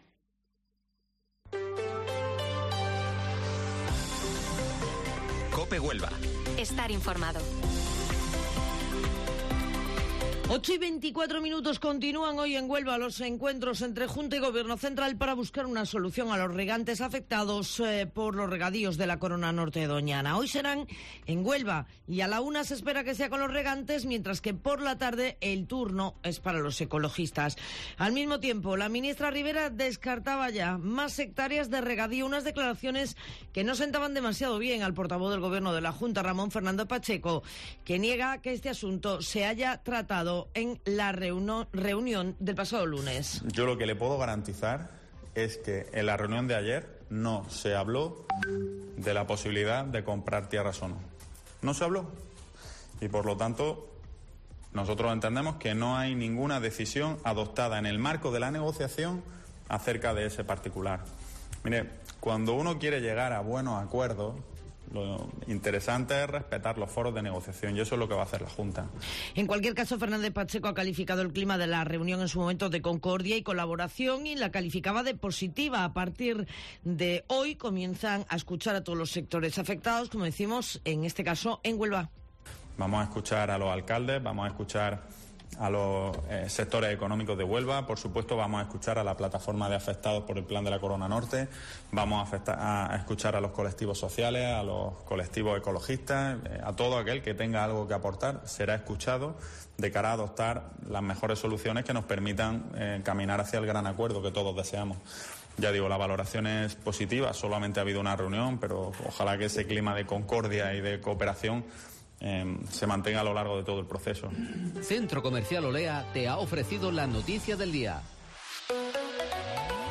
Informativo Matinal Herrera en COPE 10 de octubre